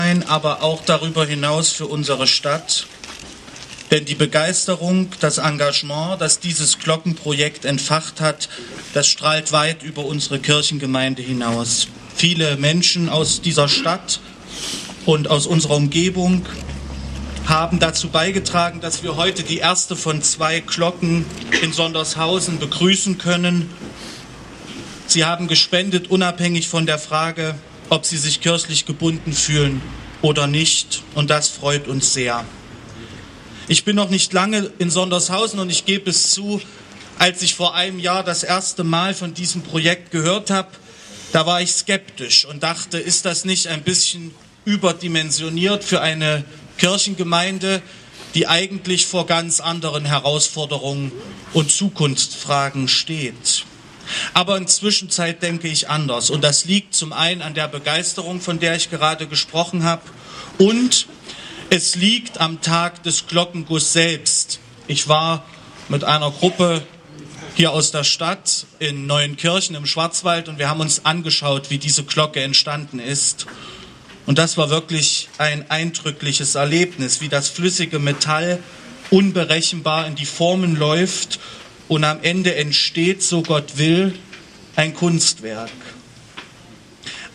Ansprache